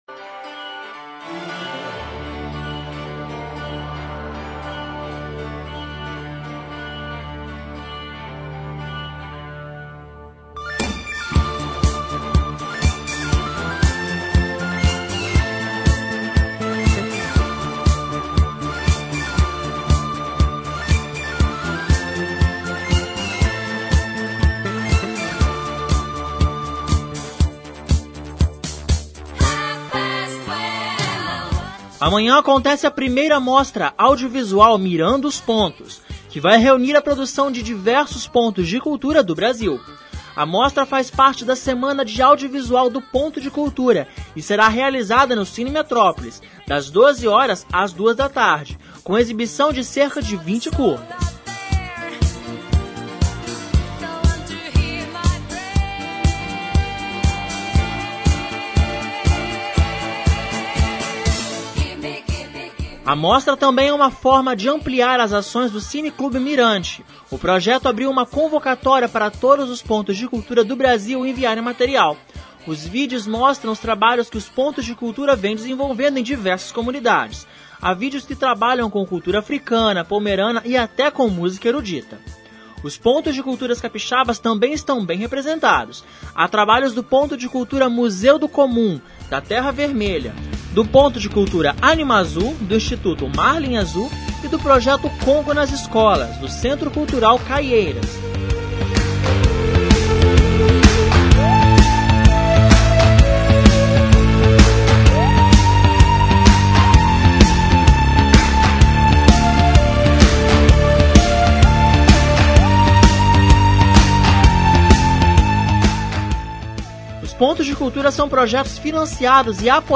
Confira mais informações na reportagem.